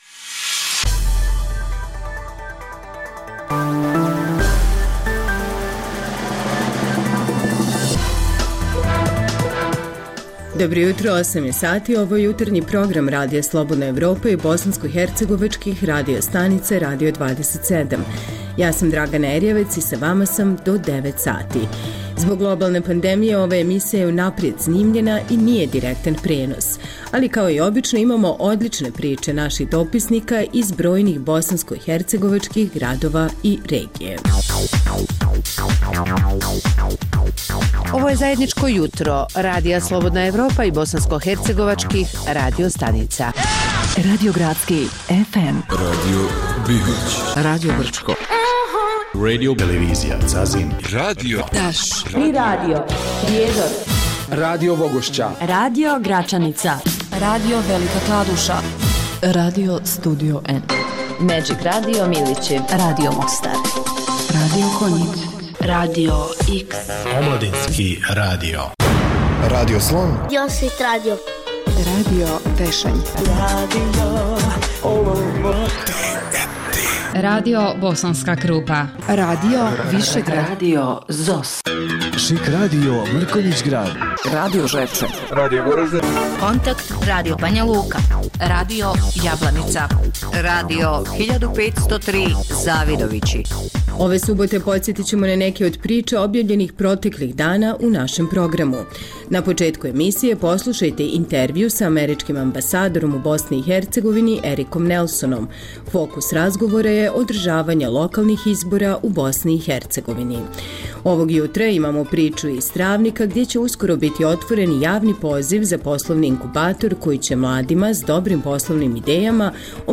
Zbog globalne pandemije ova emisija je unaprijed snimljena i nije direktan prenos. Poslušajte neke od zanimljivih priča iz raznih krajeva Bosne i Hercegovine.